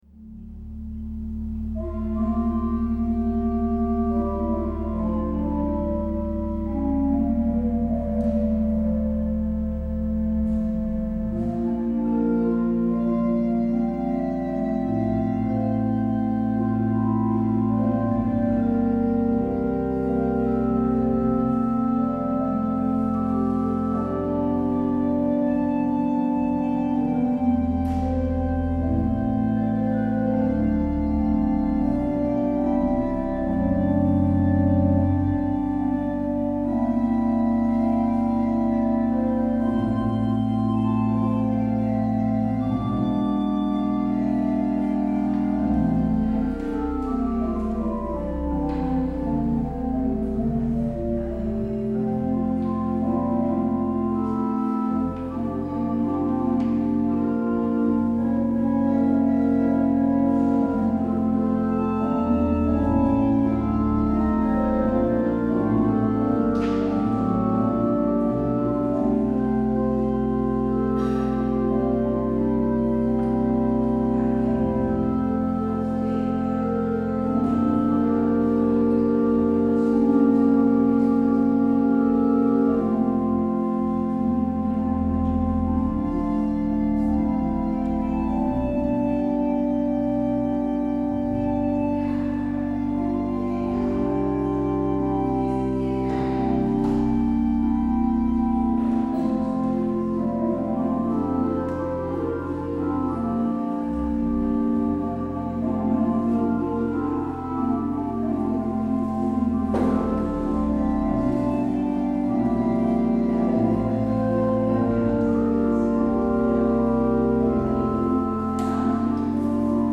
Het openingslied is NLB 713: 1, 2 en 5.
Als slotlied hoort u NLB 885.